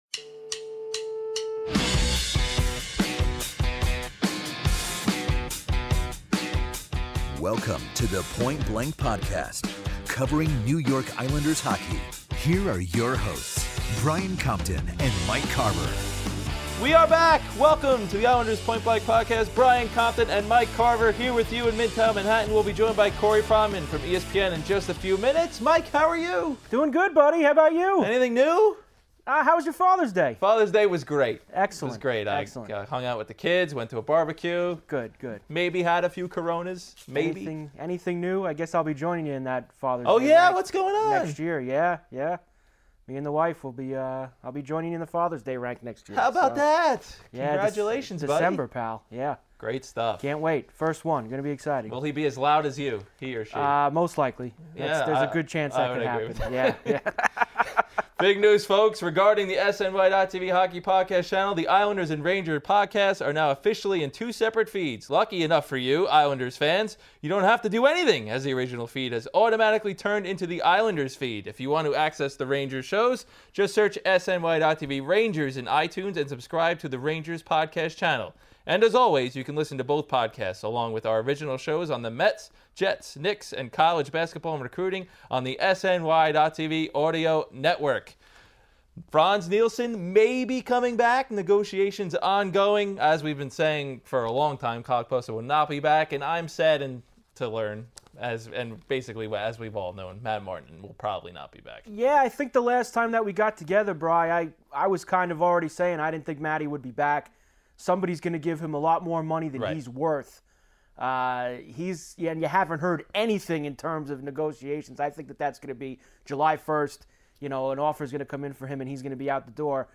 hops on the phone to chat about the upcoming NHL Draft.